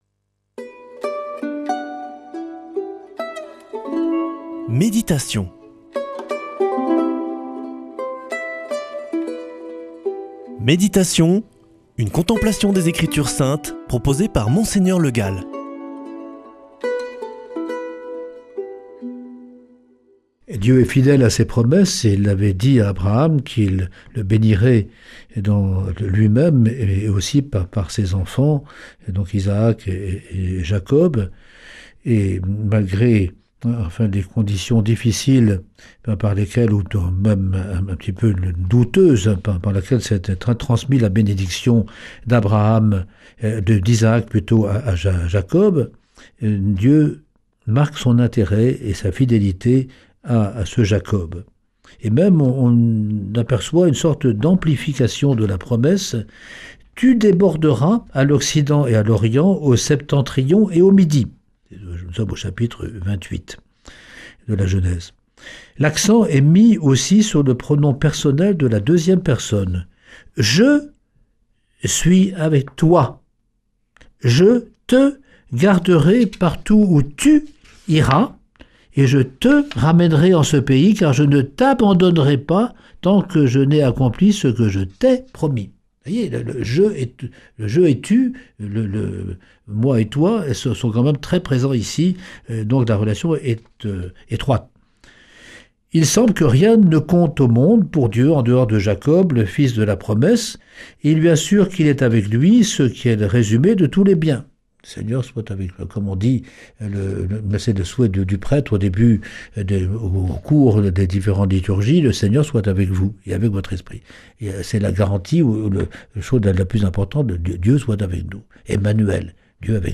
Méditation avec Mgr Le Gall
[ Rediffusion ] L’attention de Dieu pour Jacob